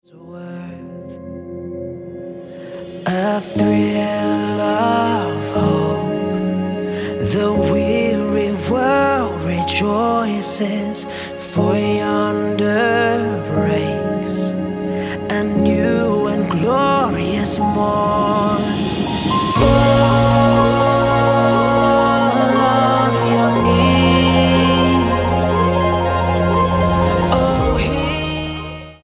STYLE: Pop
is given an appropriately slow and reverential arrangement